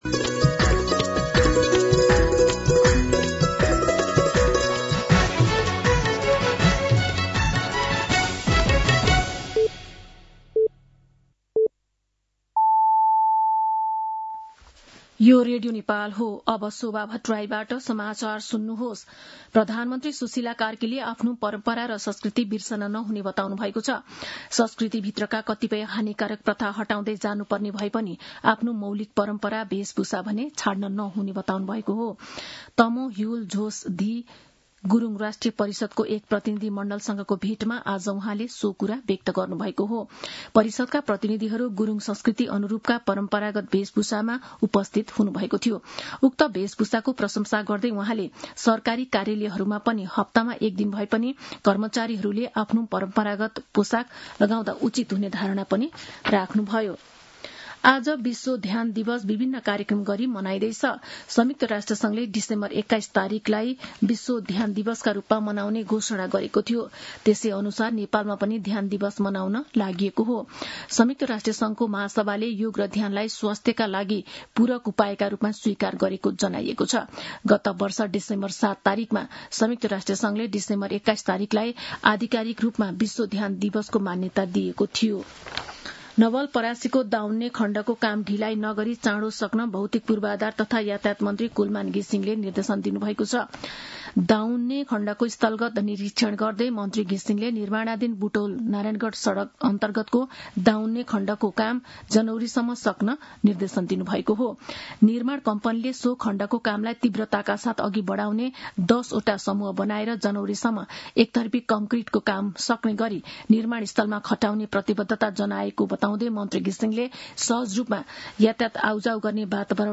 दिउँसो ४ बजेको नेपाली समाचार : ६ पुष , २०८२